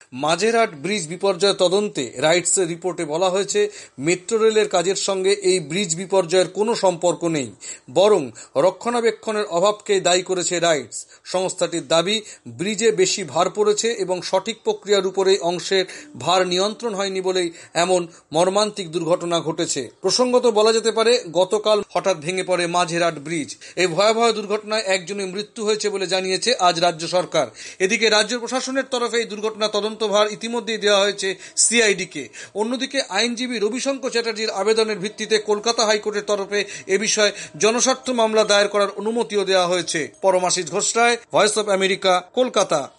কোলকাতা থেকে বিস্তারিত জানাচ্ছেন